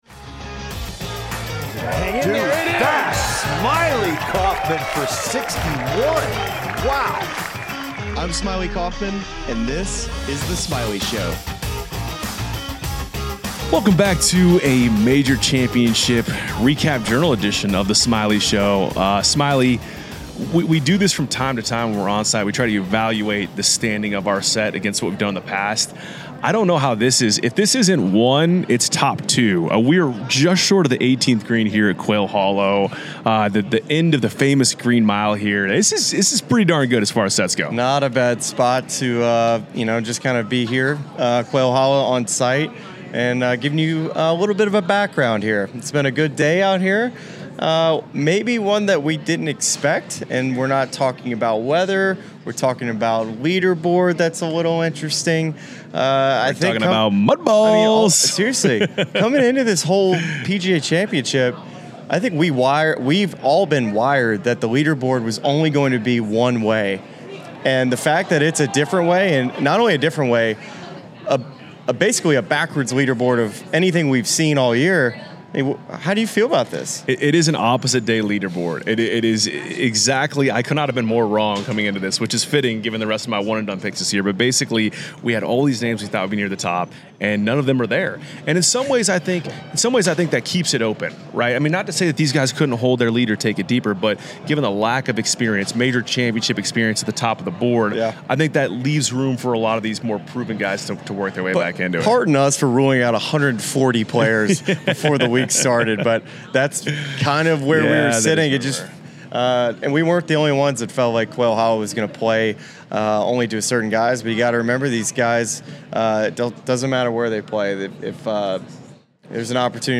From a set overlooking the 18th green at Quail Hollow